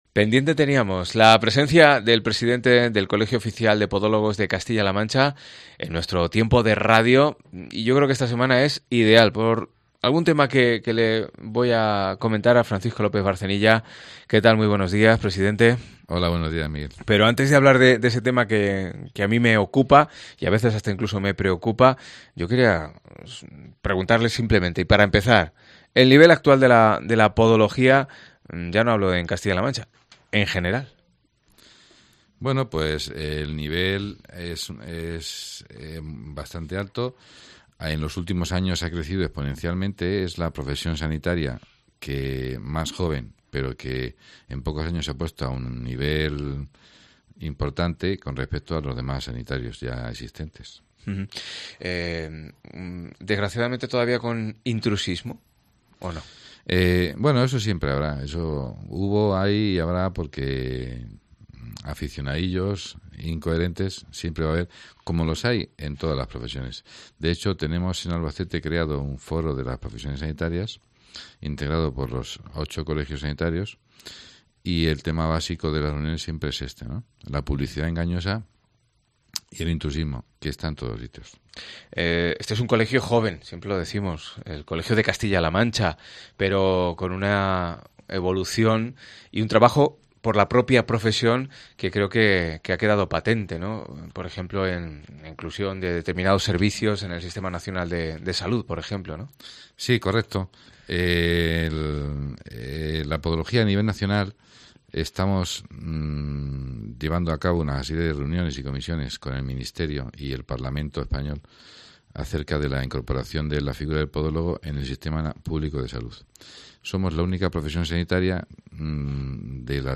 Charlamos